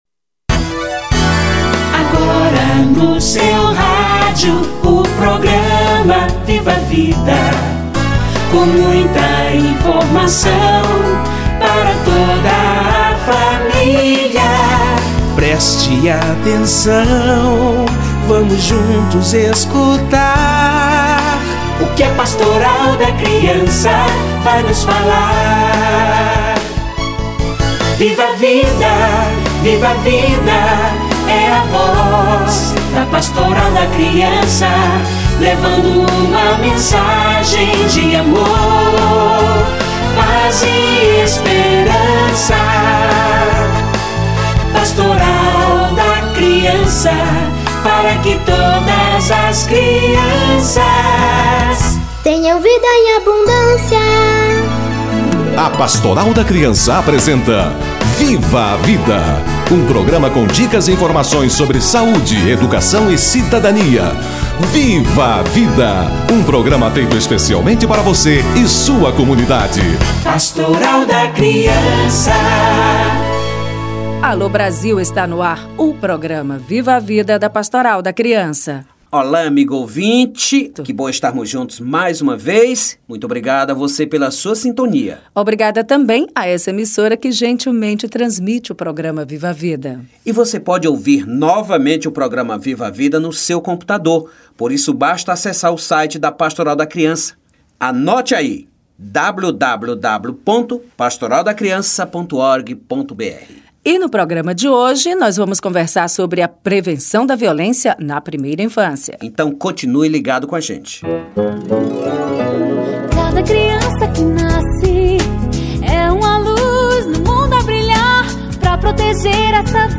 Semana Nacional de Prevenção da Violência na Primeira Infância - Entrevista